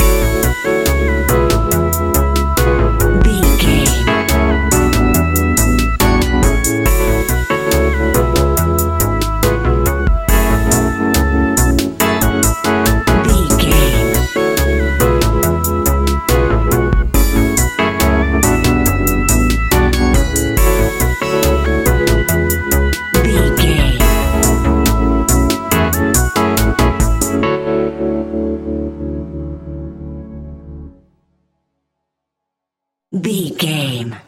Ionian/Major
laid back
Lounge
sparse
new age
chilled electronica
ambient
atmospheric
instrumentals